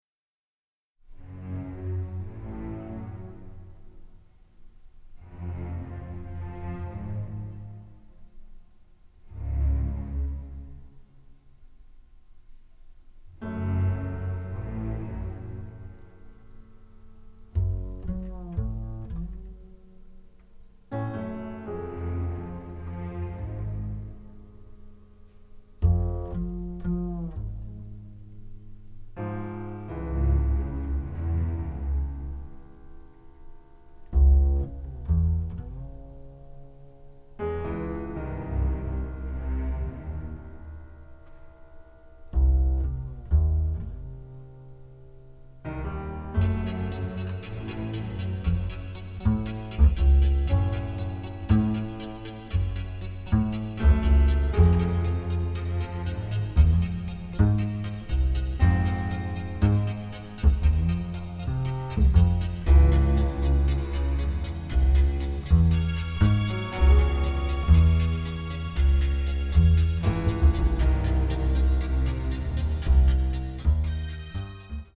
romantic noir score